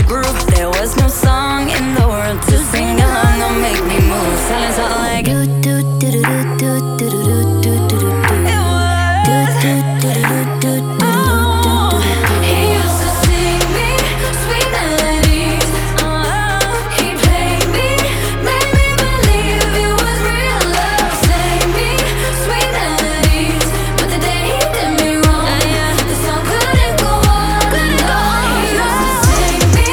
• Pop
British girl group
bouncy reggaeton drum beats